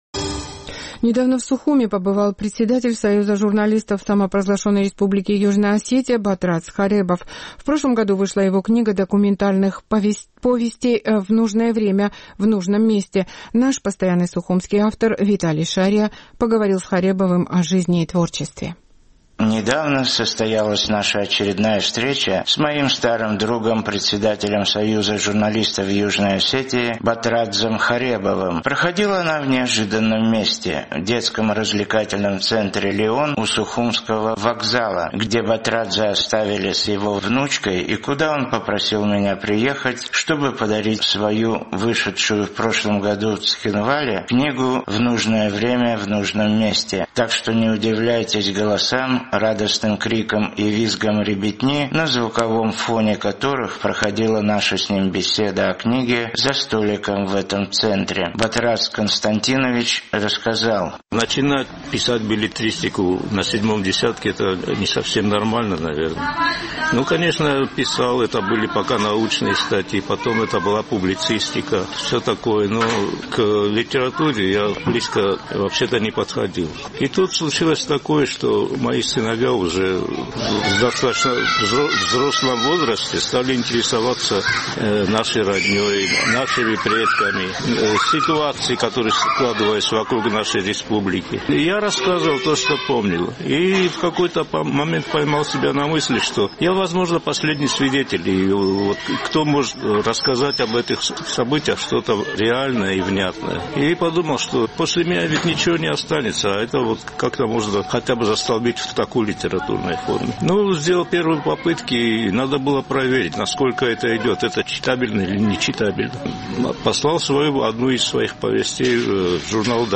Так что не удивляйтесь голосам, радостным крикам и визгам ребятни, на звуковом фоне которых проходила наша с ним беседа о книге.